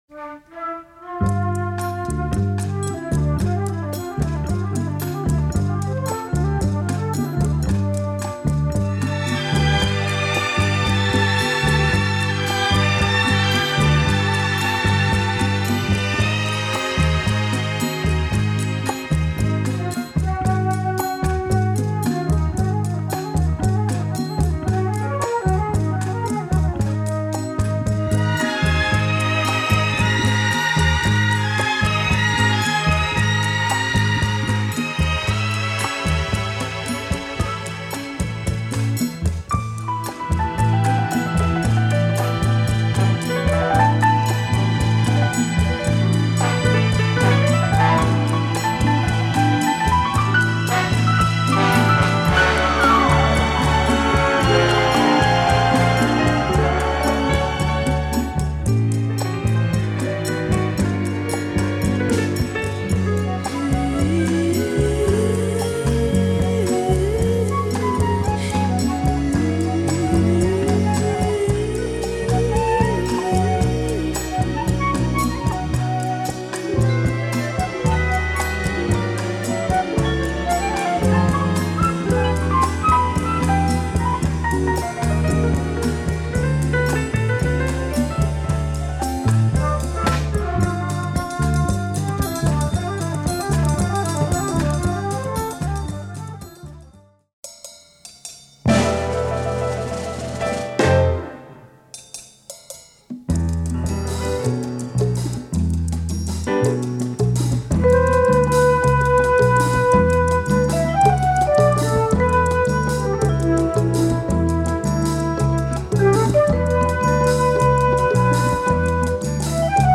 Ultra rare soundtrack album
Italian groove and jazz on 'Round Nassau'